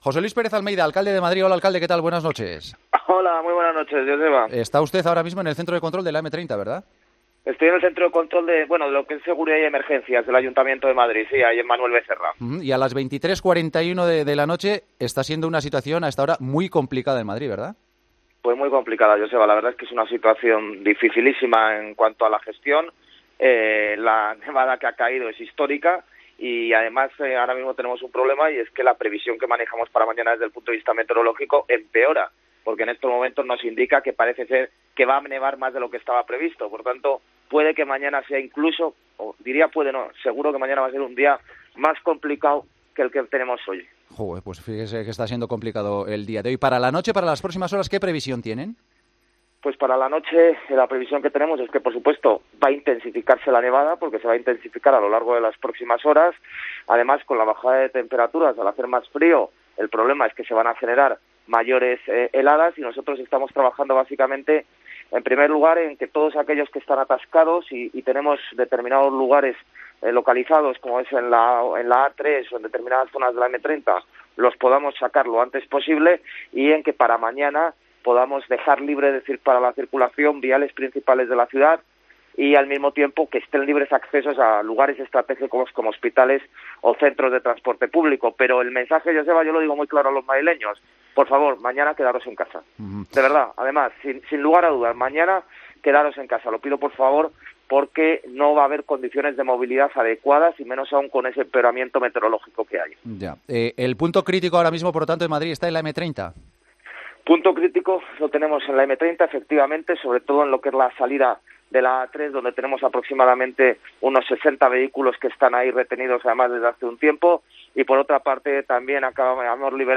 El alcalde de Madrid analiza la borrasca Filomena que está azotando Madrid y pide a los madrileños que “se queden en casa”.